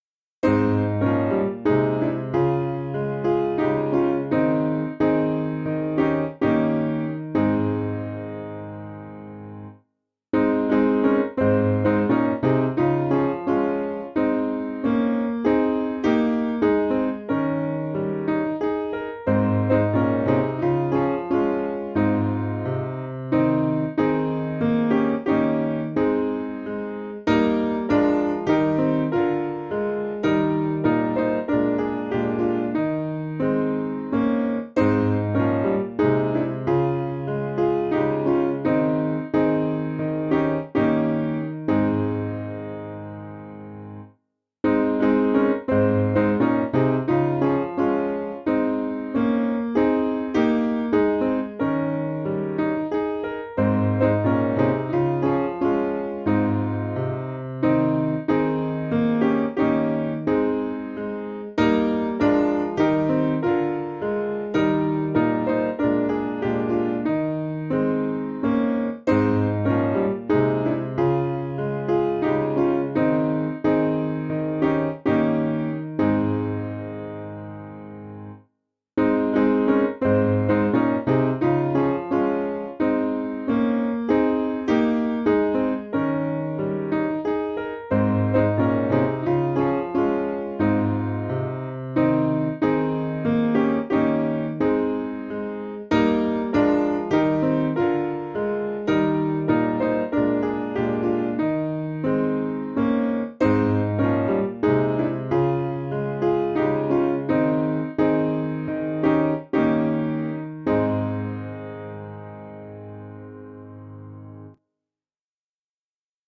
Simple Piano
(CM)   3/Ab 472.9kb